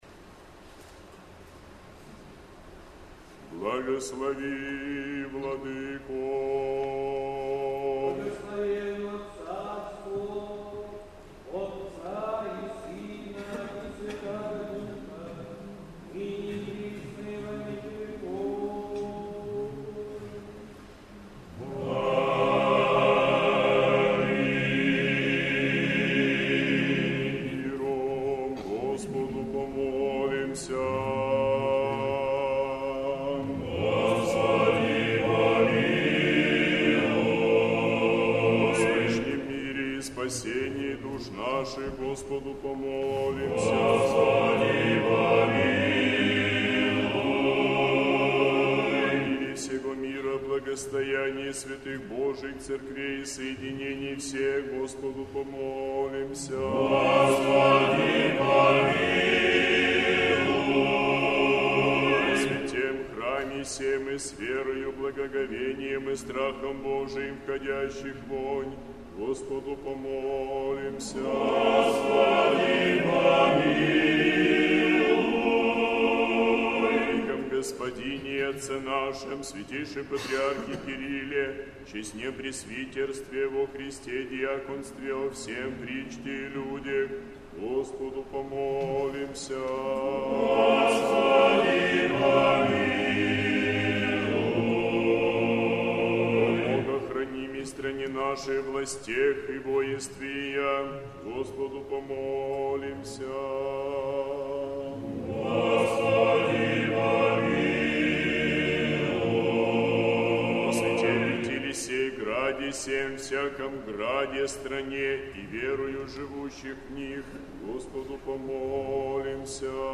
Божественная литургия в Неделю 12-ю по Пятидесятнице в Сретенском монастыре
Божественная литургия. Хор Сретенского монастыря.